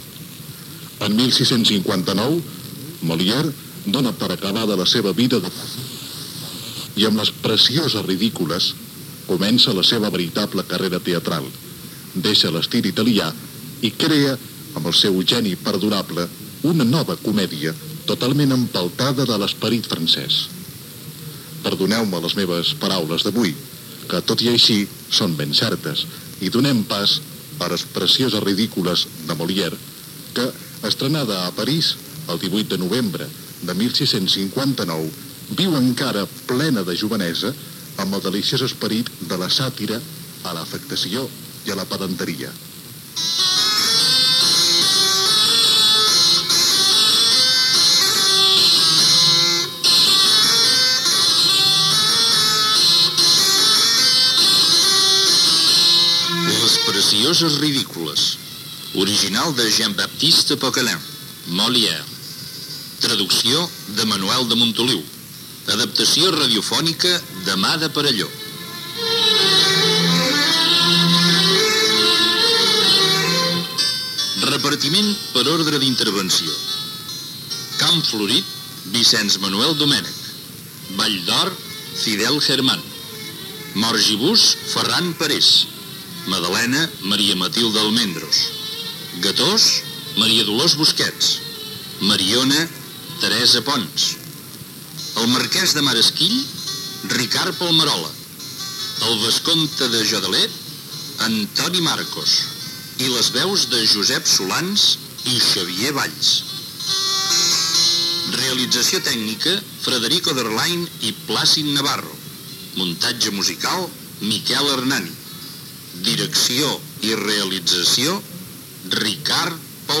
Adaptació radiofònica de l'obra «Les precioses ridícules» de Molière. Apunt sobre el teatre de Moliere, careta del programa amb el repartiment i primera escena de l'obra
Ficció